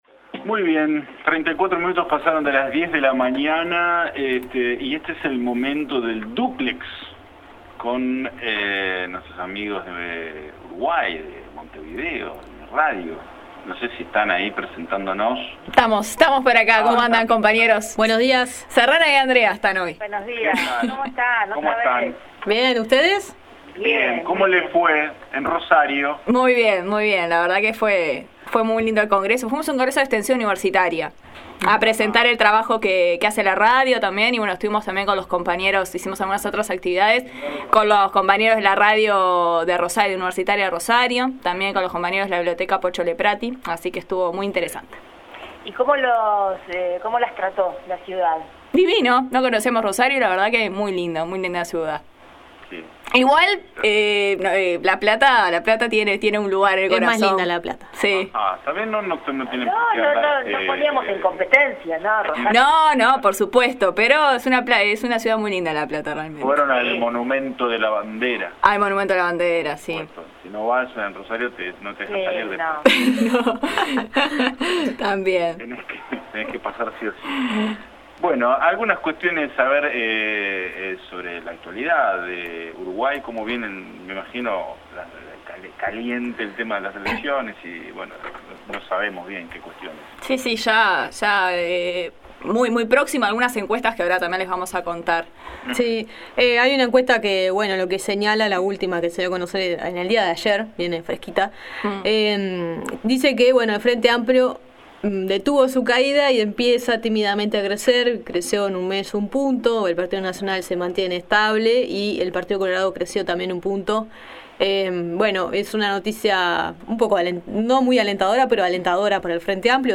En el dúplex de este miércoles con los colegas de No Se Sabe de Radio Universidad Nacional de La Plata seguimos compartiendo la información de cara a las elecciones presidenciales, se conoció una nueva encuesta donde el Frente Amplio crece en intención de voto, también hablamos sobre la publicidad oficial y la recomendación de Mujica de reducir el gasto.